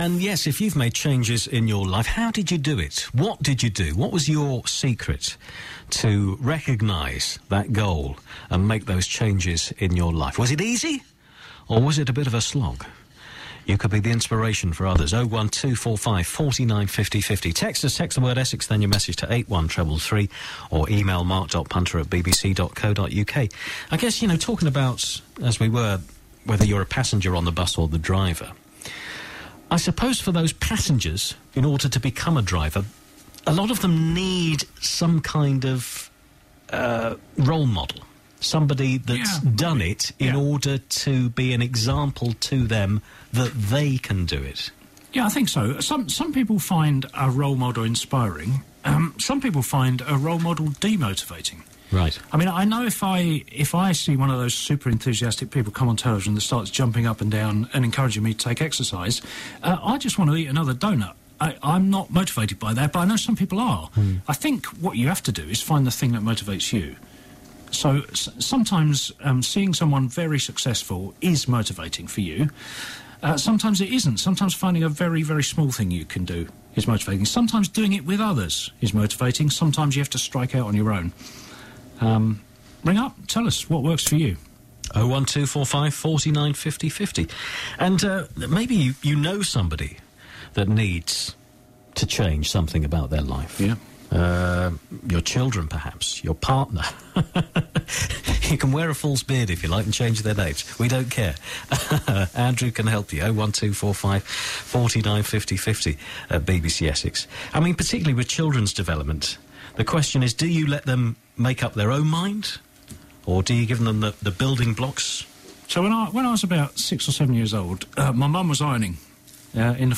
All the best bits from my Sound Advice slot on BBC Essex 24 Jan 2012.